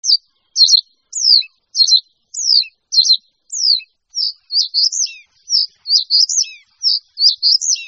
En cliquant ici vous entendrez le chant du Bruant des roseaux En cliquant ici vous entendrez le chant du Bruant des roseaux